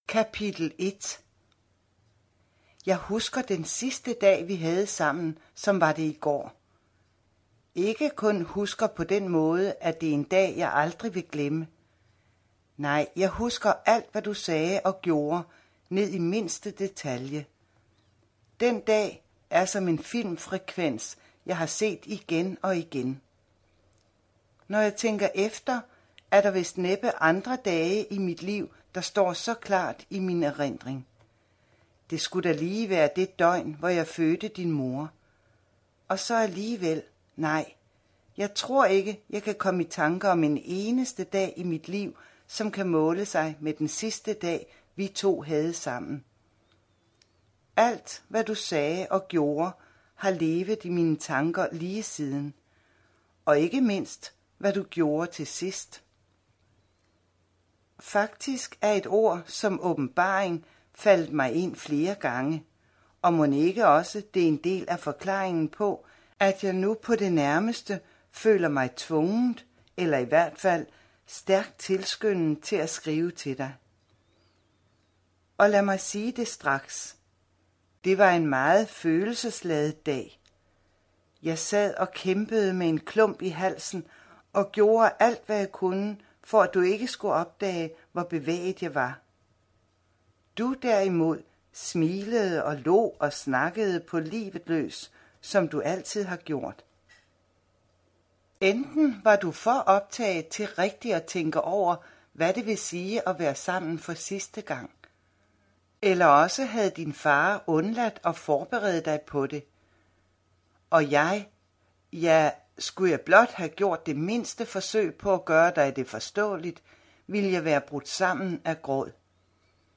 Hør et uddrag af Keramikperlerne Keramikperlerne Format MP3 Forfatter Bodil Sangill Bog Lydbog E-bog 74,95 kr.